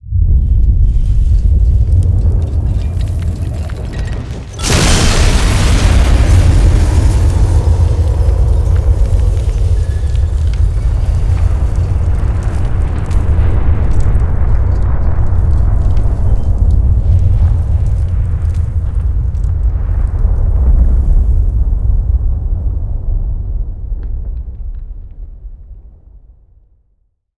fx_explosion_nukebig_demo.wav